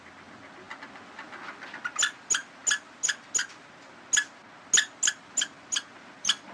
Chattering
This rapid, repetitive noise, also known as “kuk-kuk-kuk,” is a common vocalization among many species.
Chattering can vary in intensity and rhythm, reflecting their emotional state.
Chattering.mp3